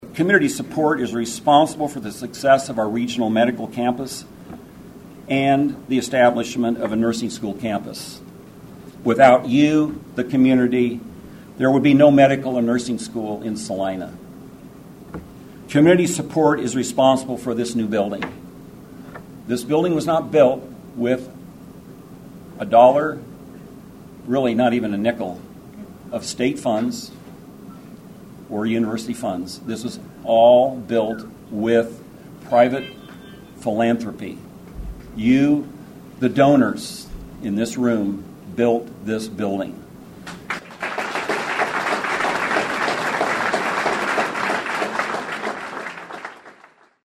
Dignitaries were among a large crowd that gathered to celebrate the opening of the Salina Health Education Center, the new home of the University of Kansas School of Medicine-Salina and the KU School of Nursing-Salina.
He spoke to the large crowd which assembled.